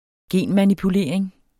Udtale [ ˈgeˀn- ]